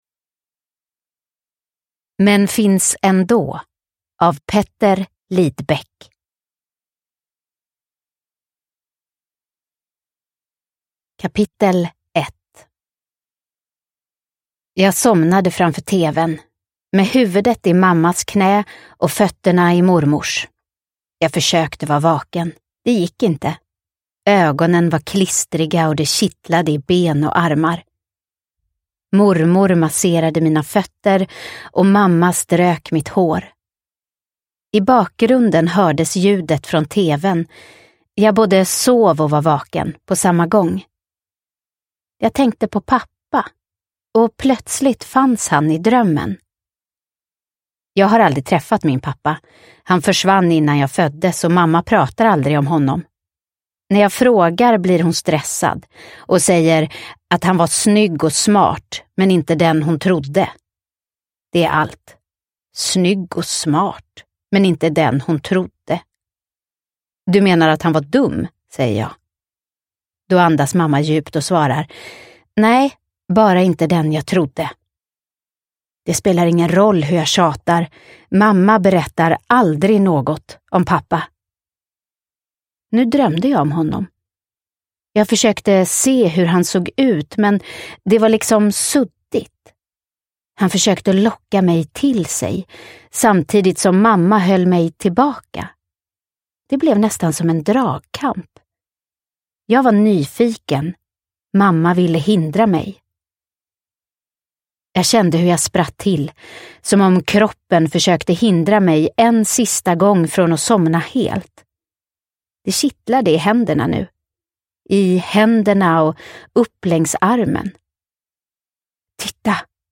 Men finns ändå – Ljudbok – Laddas ner